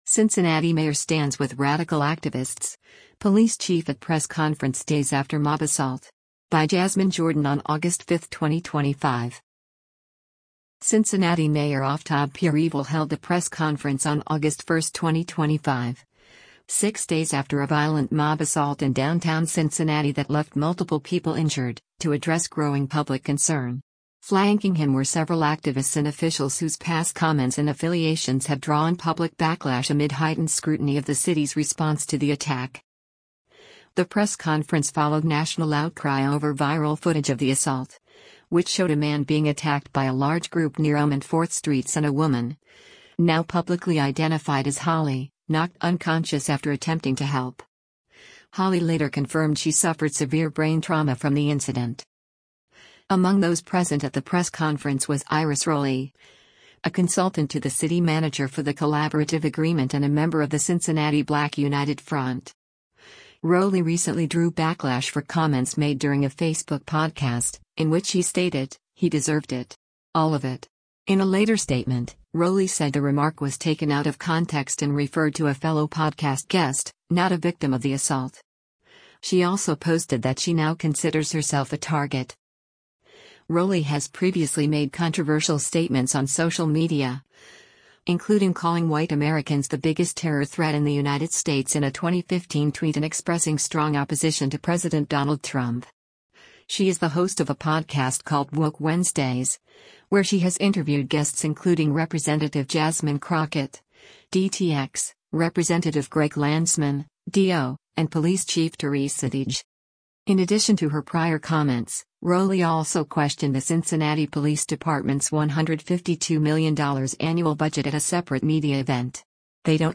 Cincinnati Mayor Aftab Pureval held a press conference on August 1, 2025, six days after a violent mob assault in downtown Cincinnati that left multiple people injured, to address growing public concern.